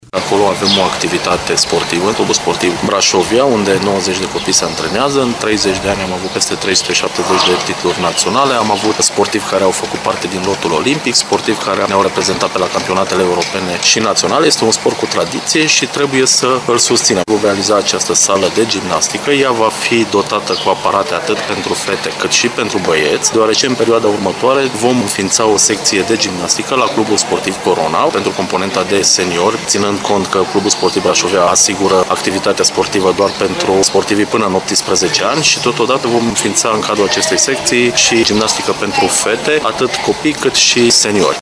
Alegerea Şcoalii Gimnaziale nr. 2 nu a fost întâmplătoare pentru realizarea acestei investiții, având în vedere că aproximativ 80% din sportivii care practică gimnastica la nivel de juniori în Braşov sunt elevi ai acestei instituţii de învăţământ, spune viceprimarul Brașovului, Mihai Costel.